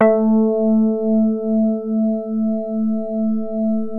JAZZ SOFT A2.wav